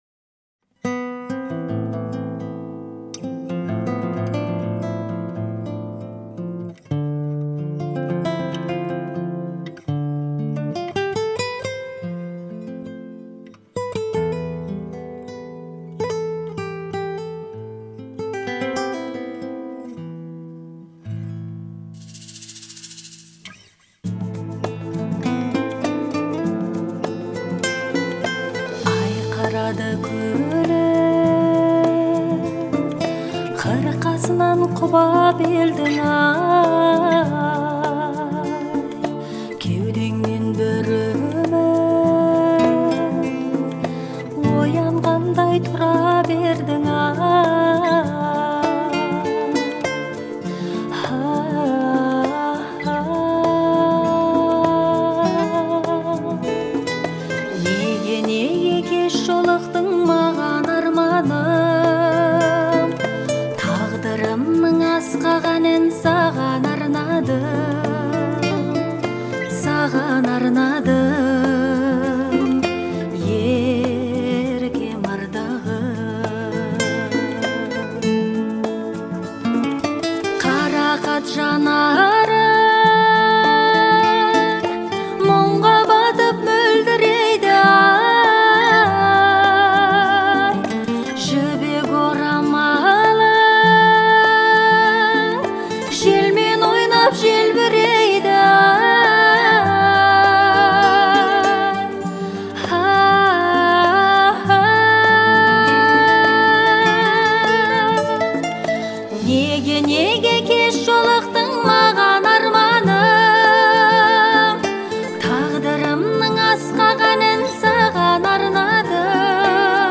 это трогательная песня казахской певицы